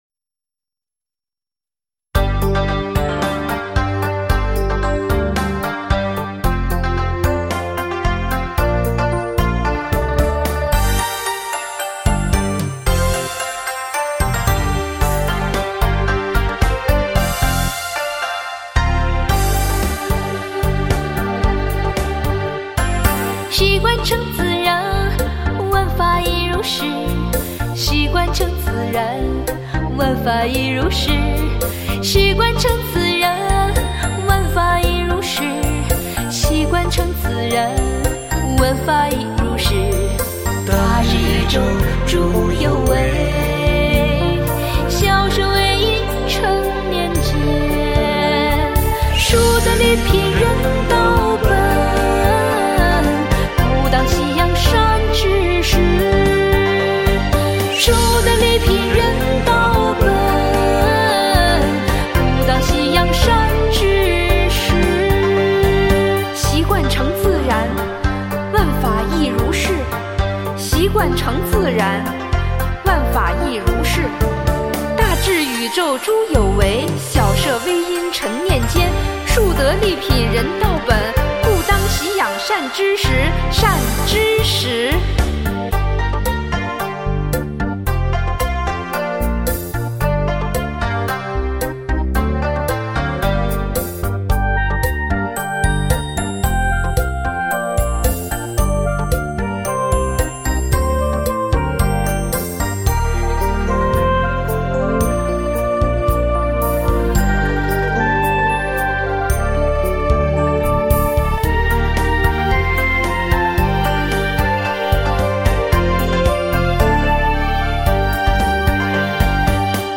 【轻快活泼的曲调，流畅、自如，深入浅出的哲理，很快将其记忆于脑海】